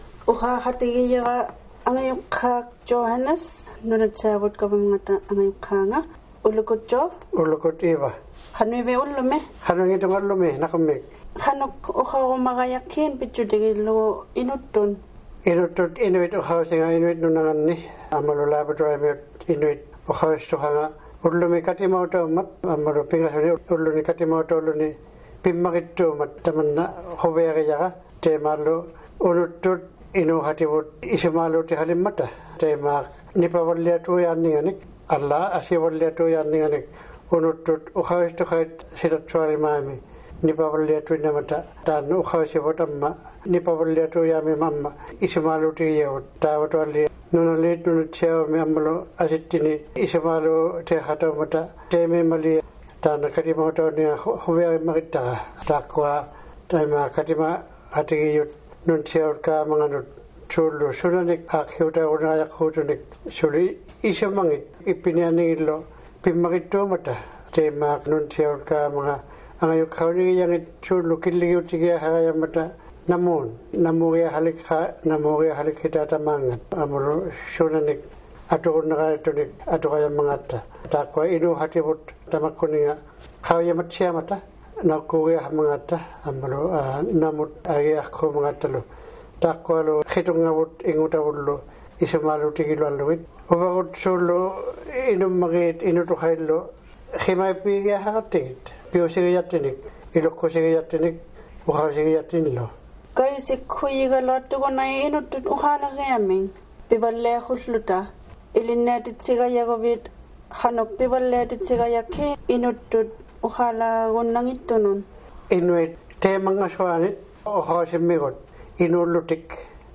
The Department of Language, Culture & Tourism is holding a Language Summit in Nain this week.